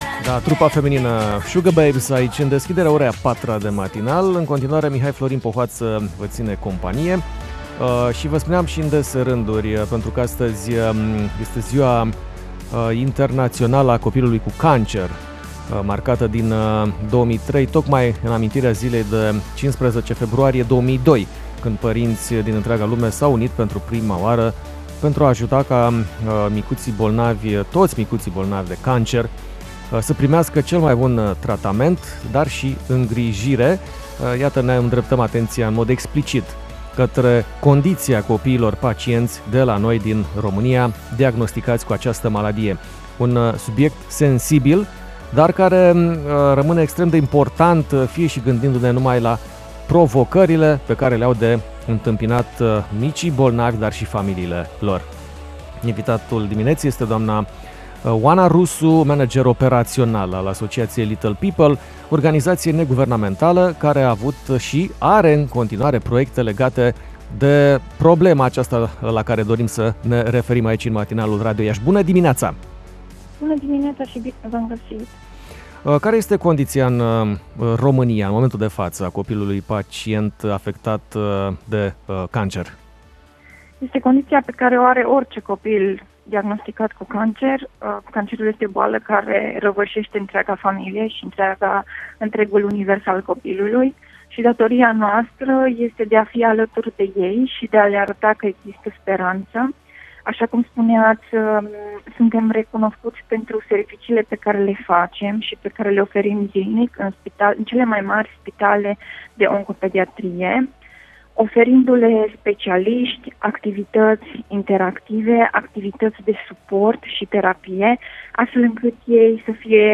Această zi atrage atenţia asupra nevoii de acces mai echitabil şi îmbunătăţit la tratament şi îngrijire pentru copiii cu cancer de pretutindeni, aspecte pe care am insistat în dialogul cu